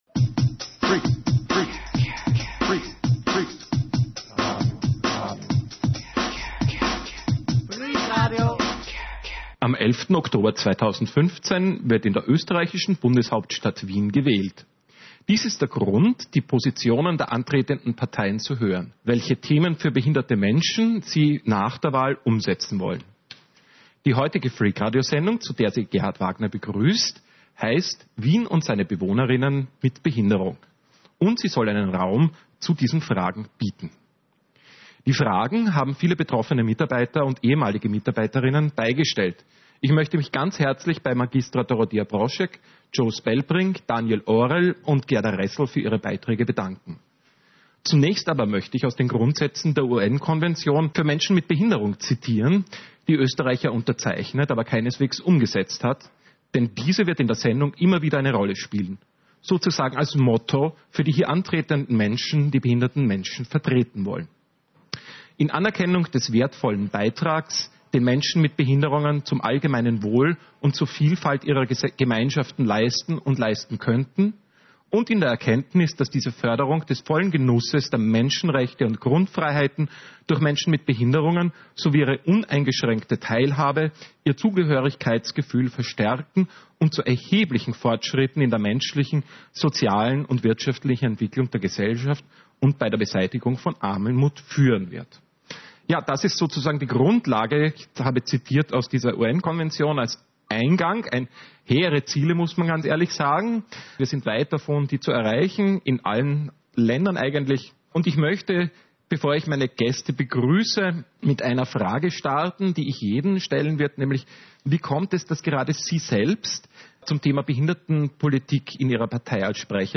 Dies ist ein Grund, die Positionen der antretenden Parteien zu hören, welche Themen für behinderte Menschen sie nach der Wahl umsetzen wollen. Diese Freak-Radiosendung, soll dazu einen Raum bieten.